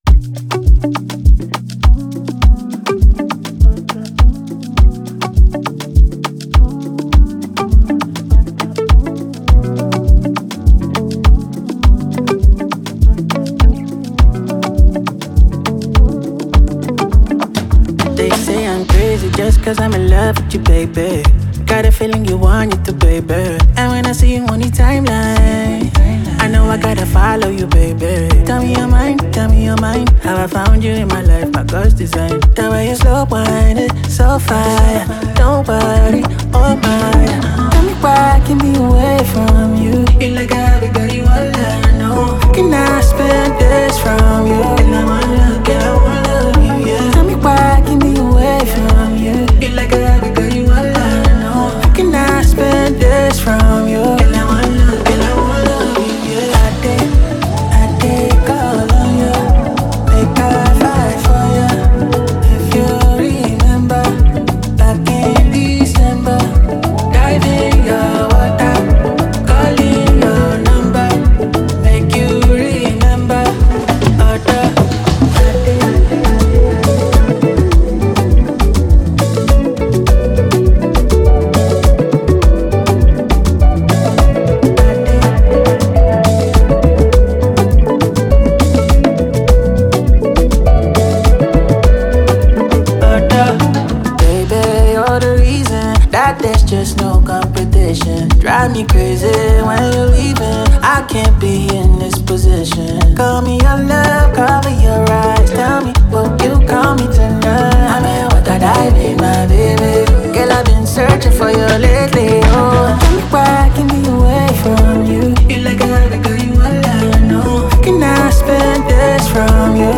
single